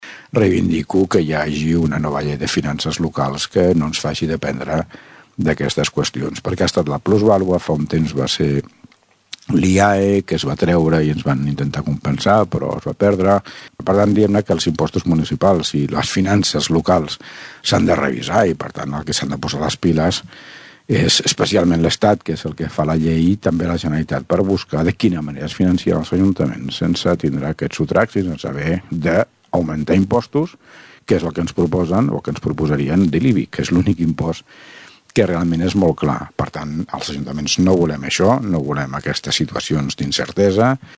alcalde novetats plusvalua
alcalde-novetats-plusvalua.mp3